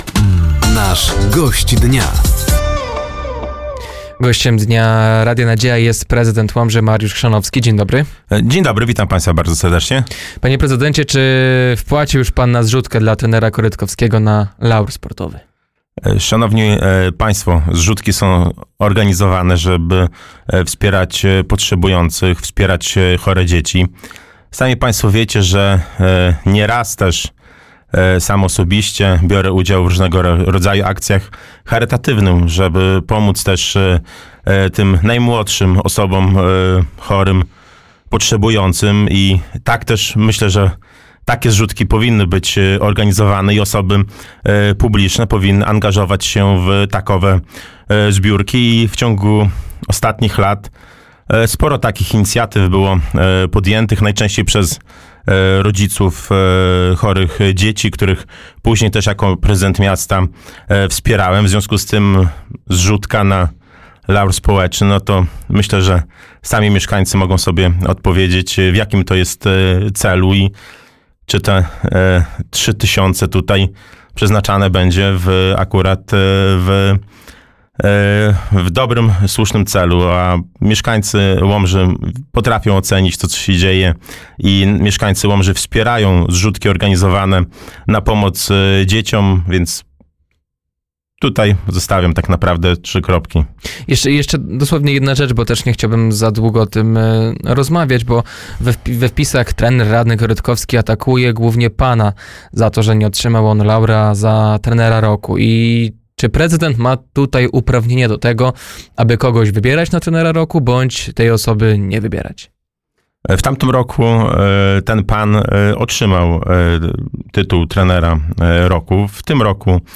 Gościem Dnia Radia Nadzieja był prezydent Łomży, Mariusz Chrzanowski. Tematem rozmowy było między innymi nowe stypendium samorządowe dla najlepszych studentów, Karta Mieszkańca Łomży i remonty parkingów.